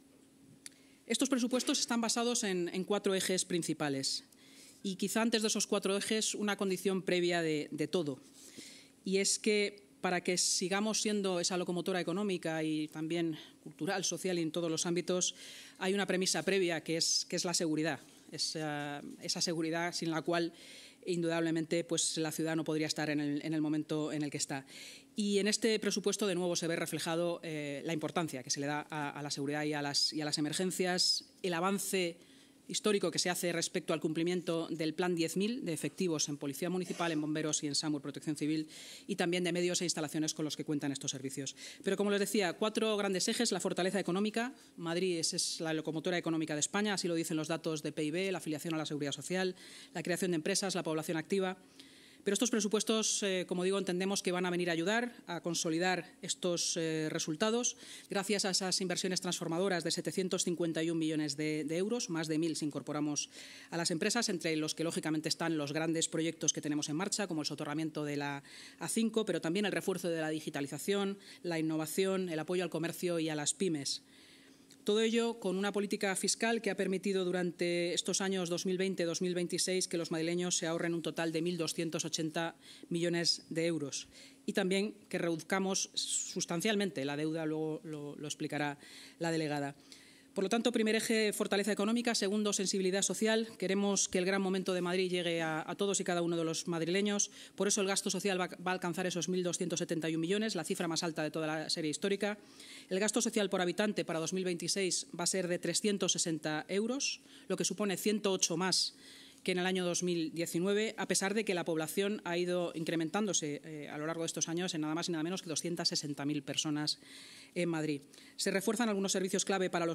La vicealcaldesa de Madrid y portavoz municipal, Inma Sanz: